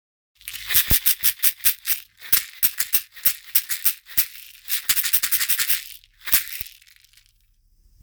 ストローがらがら 中 ダブル
>シェイカー・小物・笛
水草を編み上げたカラフルなカゴの中に、響きのよい小石や種が入っています。丸くカットしたひょうたんが底部分に組み込まれ、ジャカジャカ?♪と優しく心地よい音、自然の音が和みます。南米ででは「カシシ」とも呼ばれているシェーカー。ダブルタイプです。
素材： 水草 ヒョウタン 種 小石